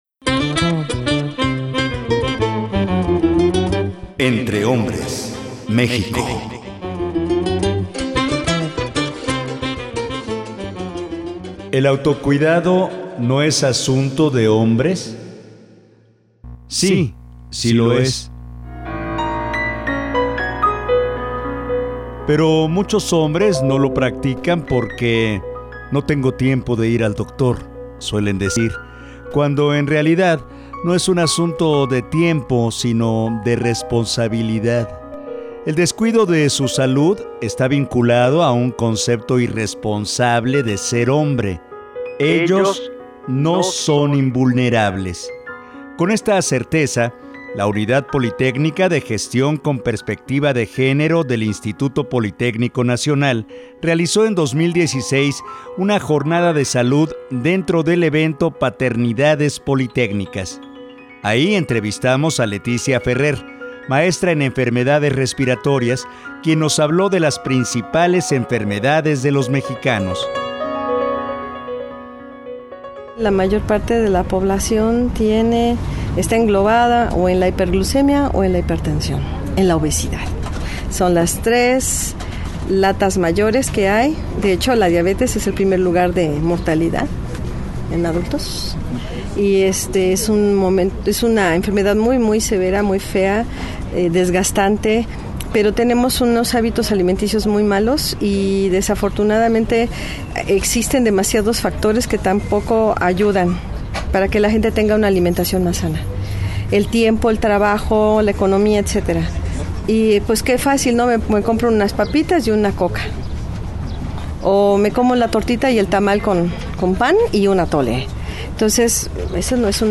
la Unidad Politécnica de Gestión con Perspectiva de Género del IPN realizó en 2016 una jornada de salud dentro del evento Paternidades Politécnicas